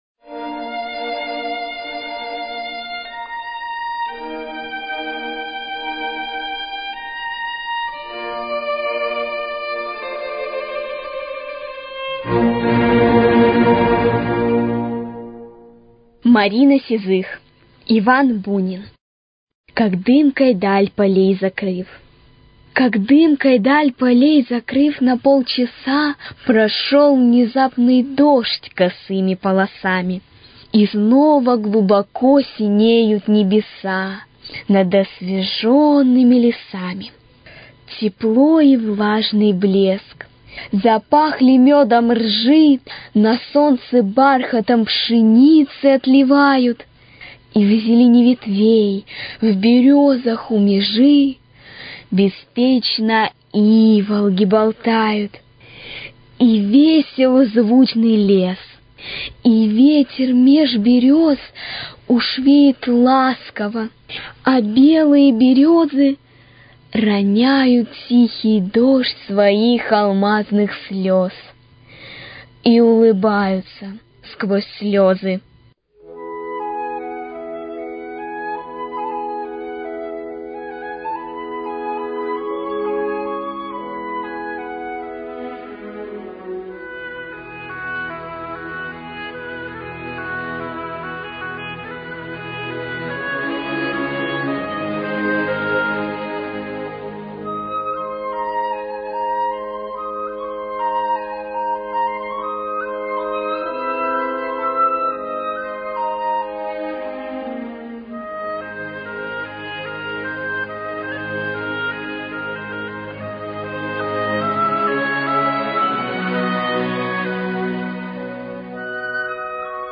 Стихи русских классиков в исполнении артистов Детского музыкального театра «Стрекоза»
В эти летние дни предлагаем послушать стихи русских классиков, посвящённые этому времени года, в исполнении юных артистов Детского музыкального театра «Стрекоза» при Музыкальном театре им. Н.М.Загурского.